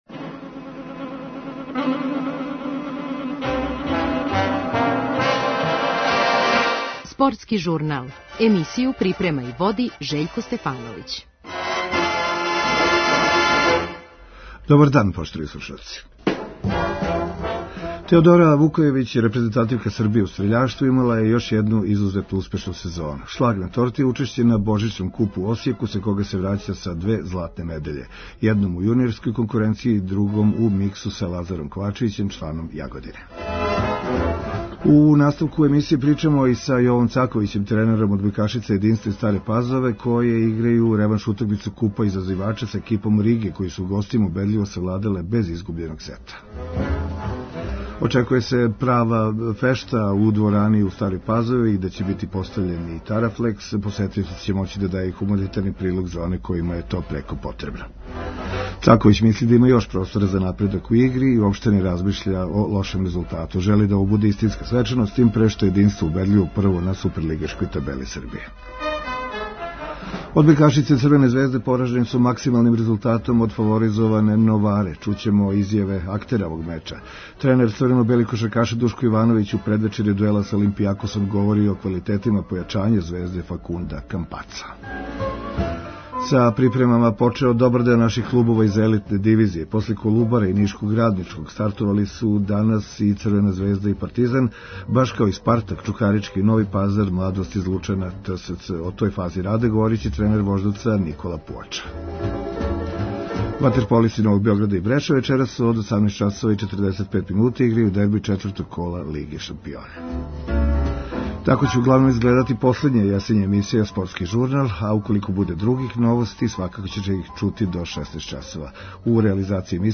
Разговарамо у данашњем `Спортском журналу` Радио Београда 1 са великом надом светског стрељаштва, не треба заборавити да је девојка са Лепенице и актуелни шампион Европе у гађању ваздушном пушком.